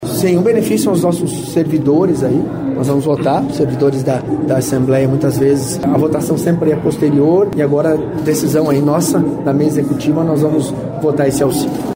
O presidente da Assembleia Legislativa, deputado Alexandre Curi (PSD), falou sobre a importância do projeto antes da votação.